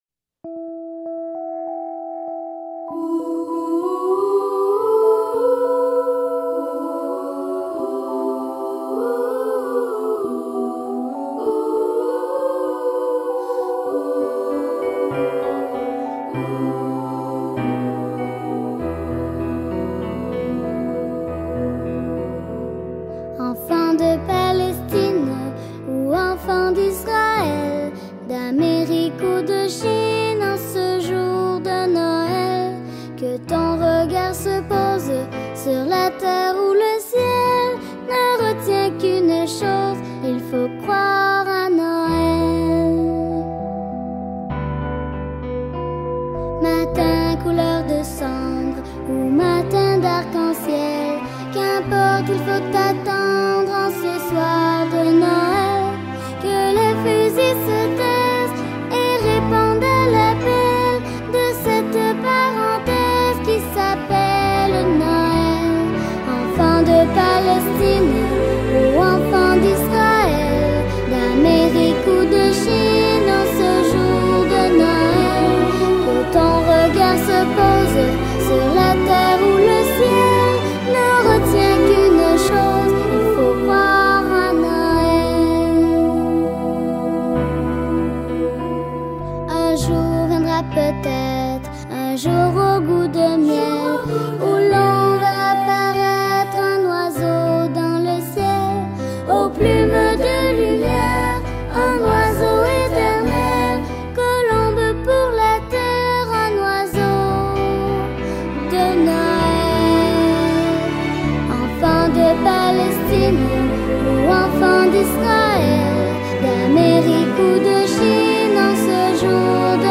Ce matin, tous les enfants de l' école se sont rendus à l'église pour la célébration de Noël.
Vous pouvez écouter le chant en PJ chanté ce matin 🌟🎶 Photos (29) Audio (1) Votre navigateur ne peut pas lire ce son : Download it 2 Noel des enfants du monde.mp3 Ecrire un commentaire Aucun commentaire